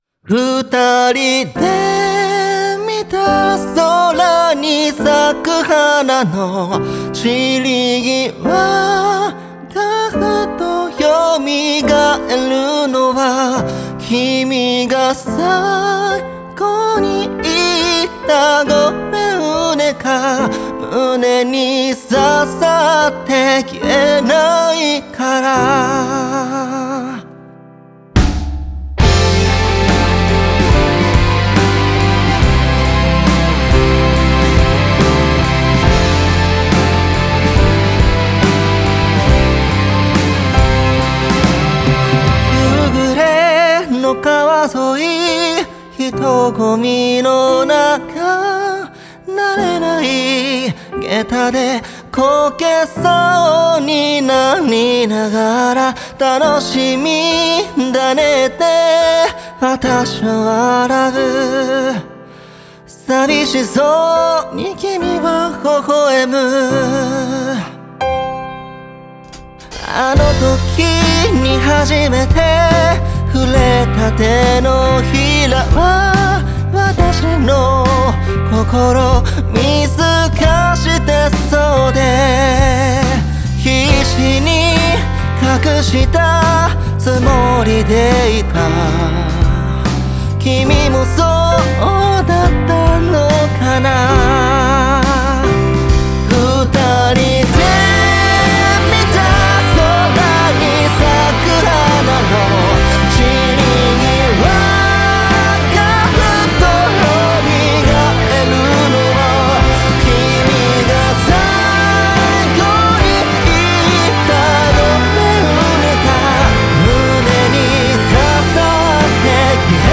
鳥取市発4人組ロックバンド。